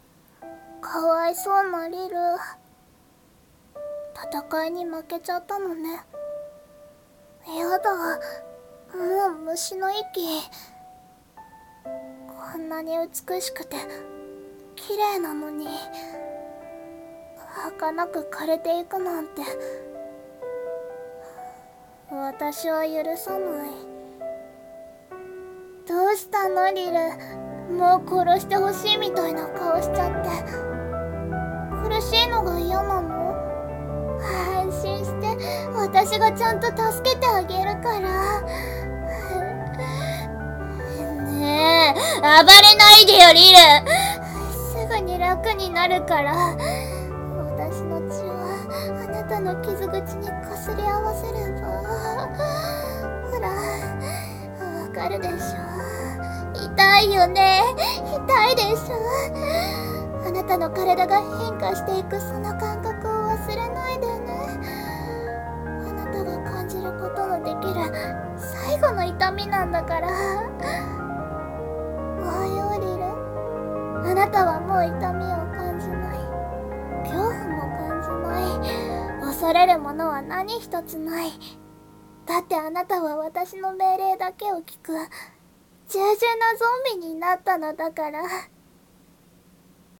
声劇【感覚】※悪役声劇